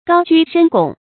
高居深拱 gāo jū shēn gǒng 成语解释 谓高居帝位，垂拱而治。